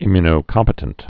(ĭmyə-nō-kŏmpĭ-tənt, ĭ-my-)